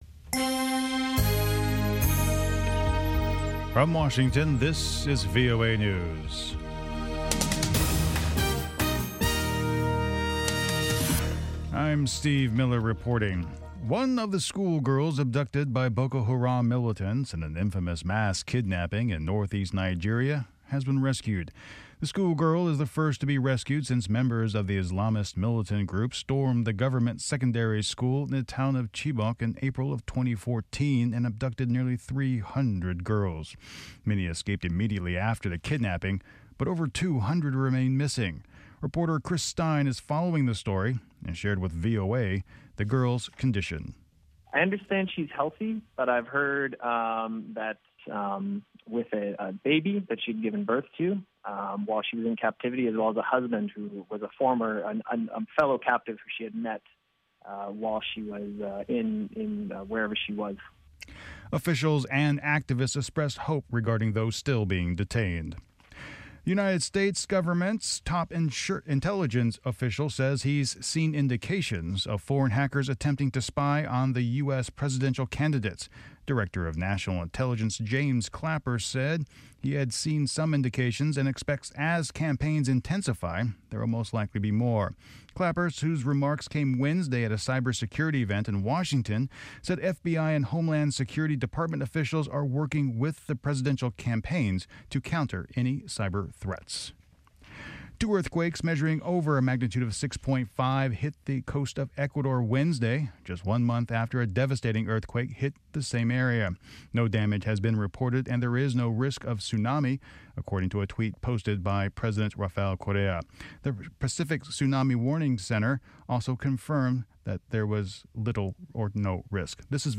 1800 Hourly Newscast English